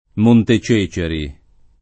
Monte Ceceri [ m 1 nte ©%© eri ] (meno com.